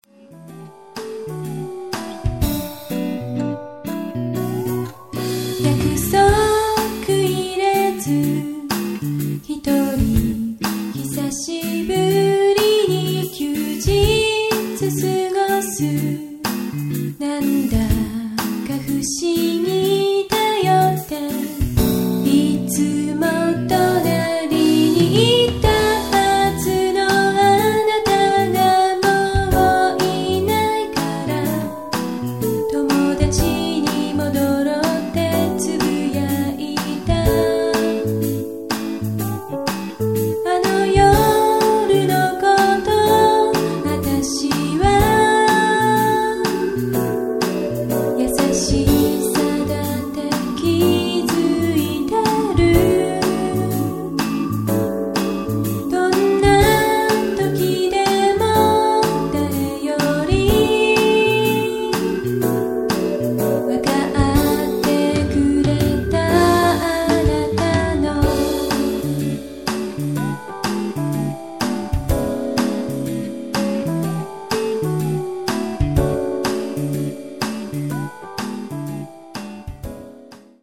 女性シンガー